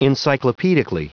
Prononciation du mot encyclopedically en anglais (fichier audio)
Prononciation du mot : encyclopedically